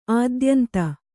♪ ādyanta